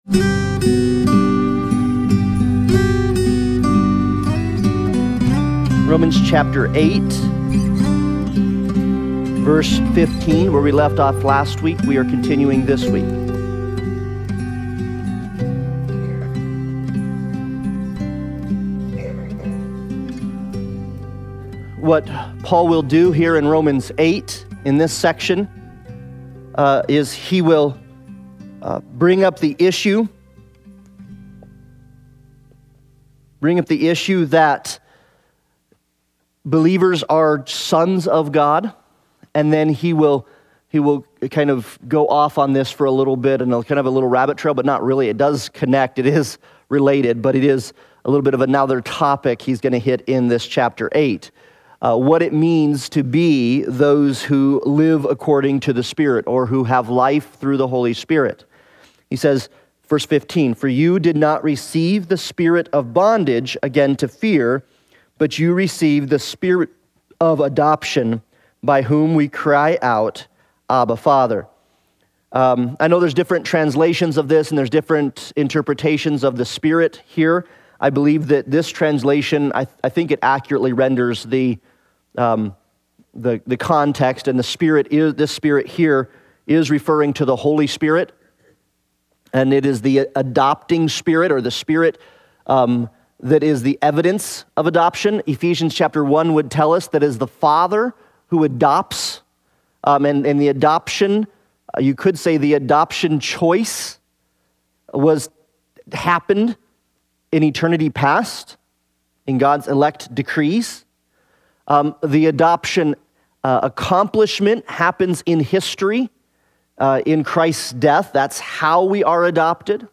Passage: Romans 8:15-23 Service Type: Sunday Bible Study